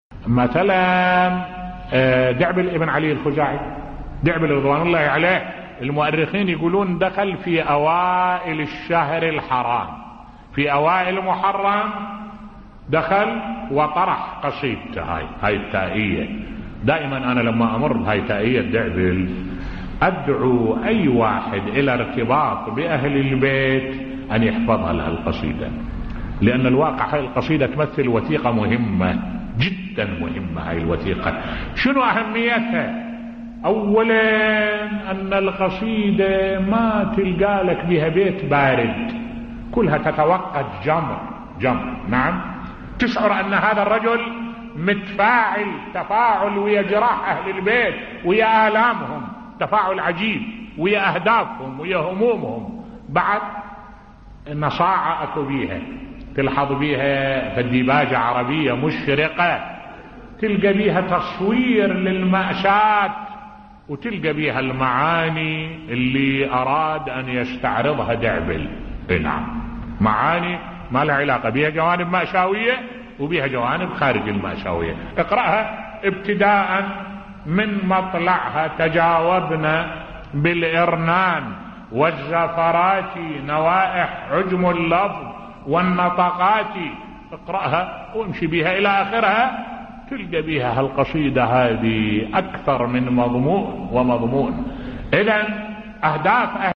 ملف صوتی ادعوا كل محب لأهل البيت لحفظ هذه القصيدة بصوت الشيخ الدكتور أحمد الوائلي